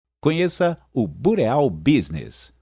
Está em anexo a pronúncia do nome da empresa e a pronúncia do site